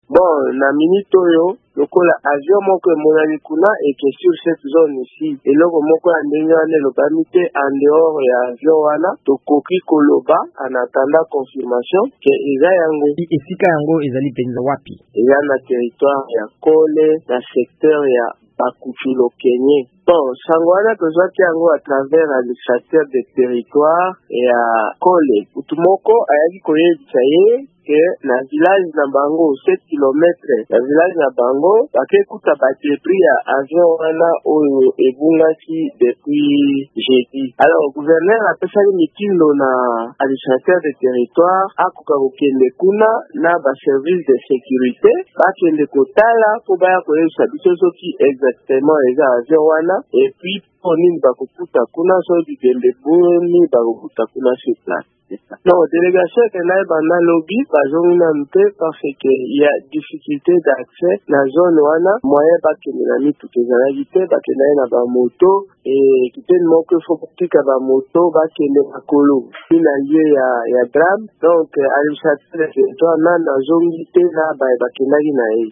Kolanda maloba ba bango, ekoki kozala Antonov 72 elimwaki uto mokolo ya minei nsima na kolongwa aéroport ya Goma. Mpepo yango emekaki bakengeli mpe  mokumbi motuka ya president Félix Tshisekedi. VOA Lingala ezwaki na nzela ya nsimba mokambi ya Assemblée provinciale ya Sankuru, Benoit Olamba.